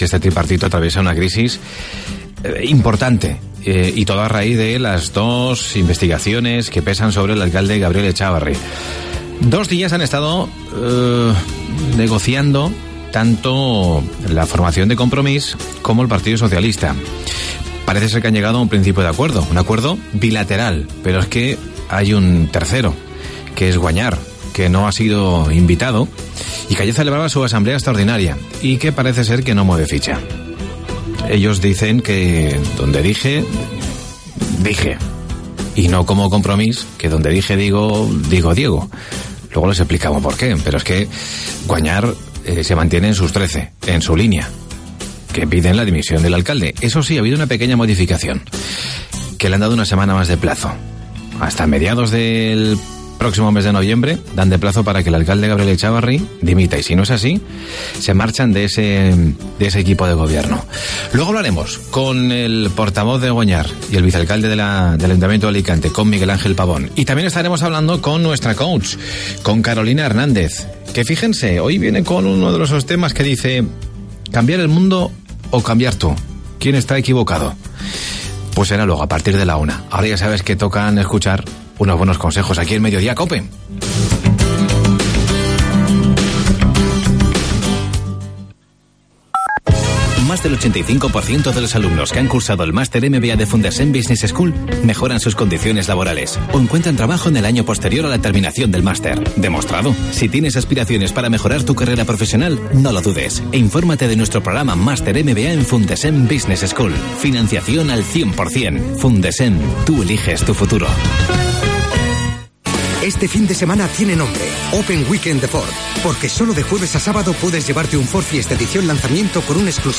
AUDIO: Miguel Ángel Pavón, portavoz de Guanyar, habla sobre el acuerdo alcanzado por PSOE y Compromís para refundar el pacto de gobierno en el Ayuntamiento de...